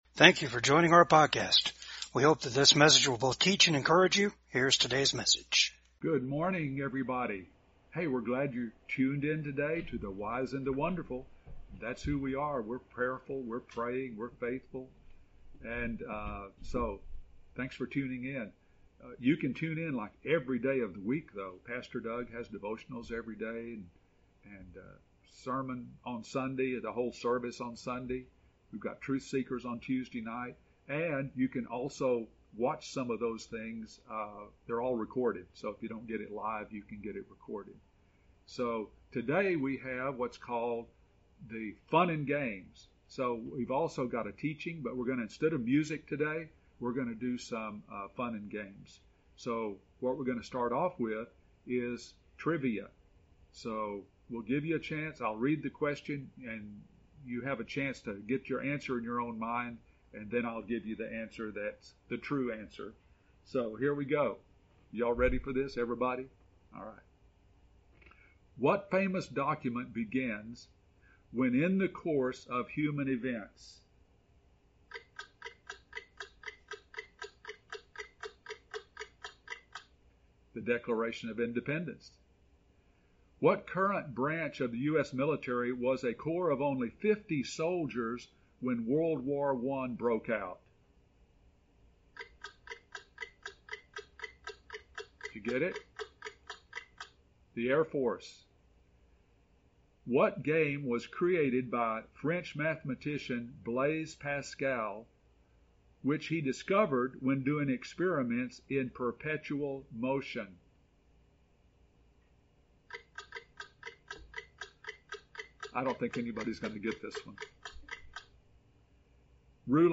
Service Type: VCAG WEDNESDAY SERVICE RESIST THE DEVIL LIKE JESUS DID BY ABIDING IN GOD AND HIS WORD.